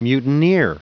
Prononciation du mot mutineer en anglais (fichier audio)
Prononciation du mot : mutineer